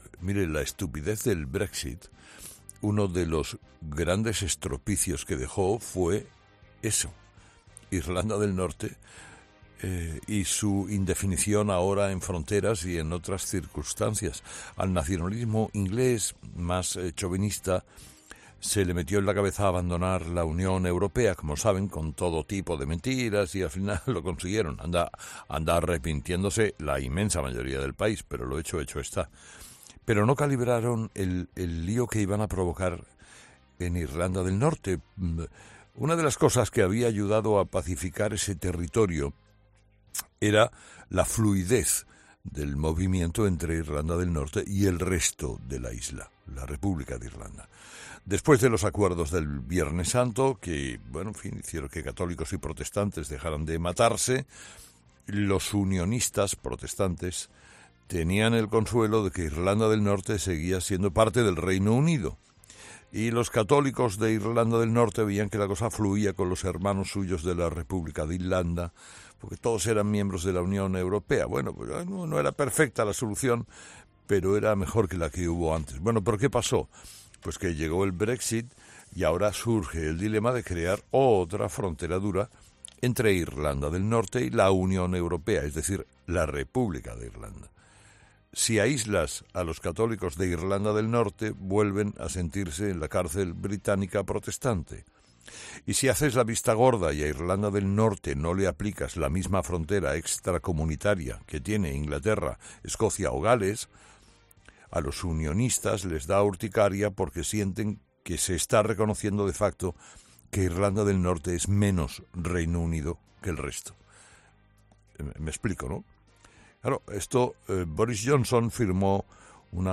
Carlos Herrera desgrana las consecuencias del 'brexit' en la isla de Irlanda